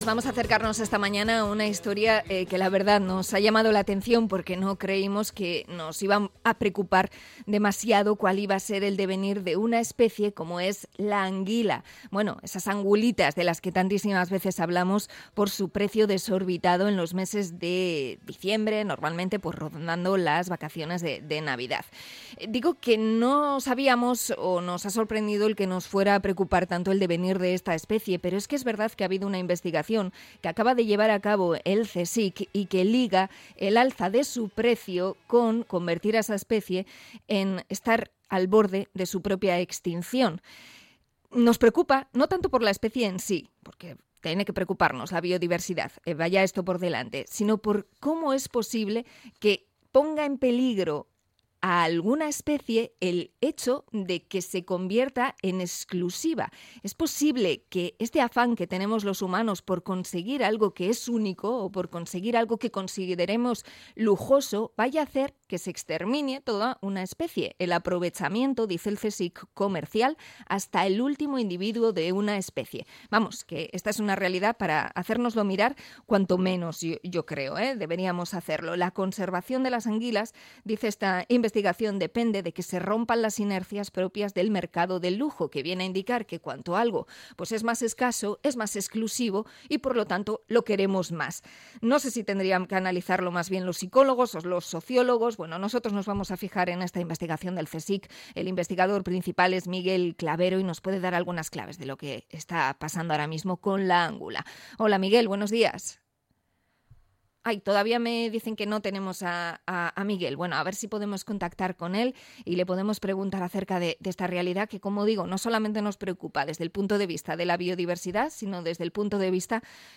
Entrevista al CSIC por el peligro de extinción de la anguila